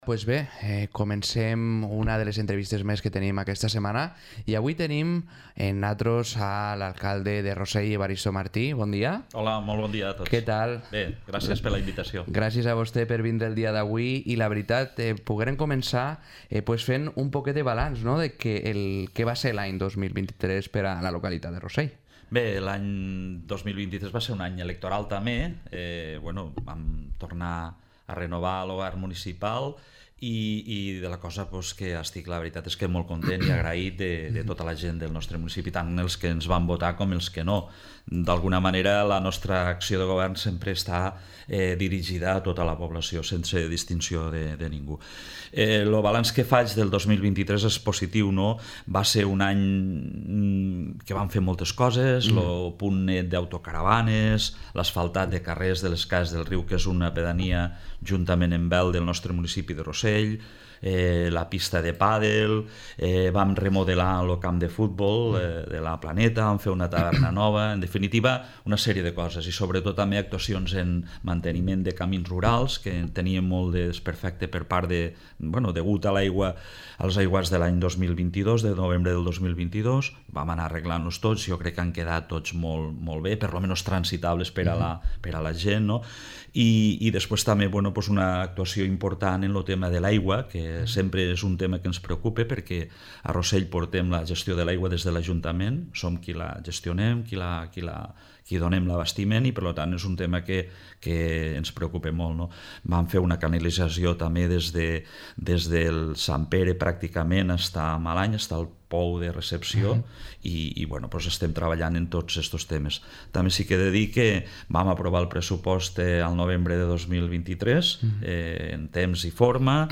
Entrevista a Evaristo Martí, alcalde de Rossell